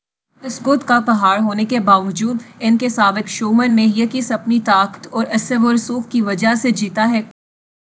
deepfake_detection_dataset_urdu / Spoofed_TTS /Speaker_04 /261.wav